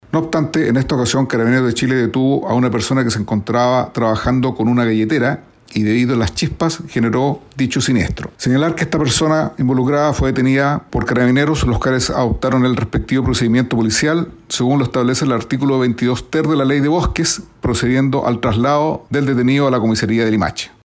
Así lo detalló el director regional de Conaf, Mauricio Núñez.